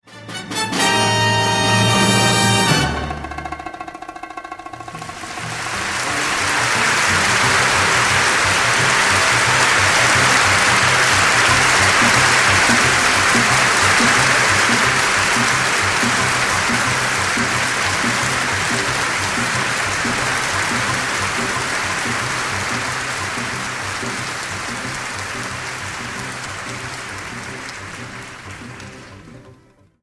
Genre: World
The Legendary Audiophile Live Recording is now on XRCD!